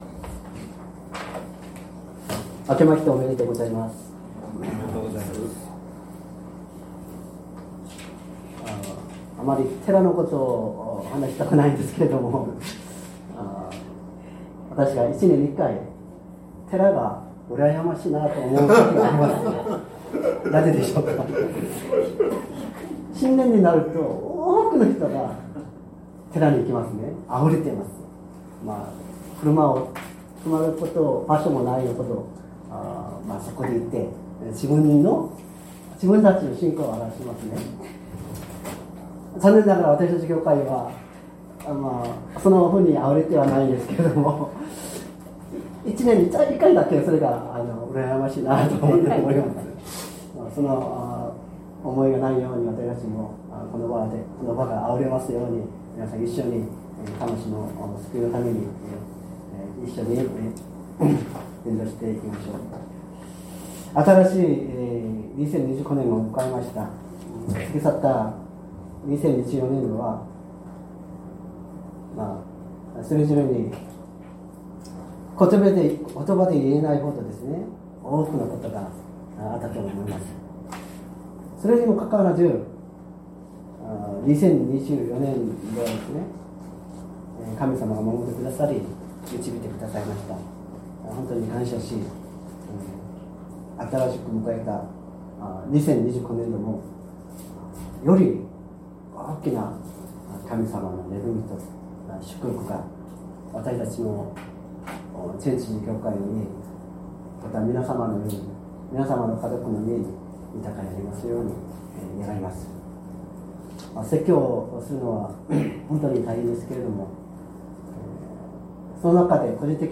説教アーカイブ 2025年01月05日朝の礼拝「愛の中で真理を語ろう キリストに至るまで」
音声ファイル 礼拝説教を録音した音声ファイルを公開しています。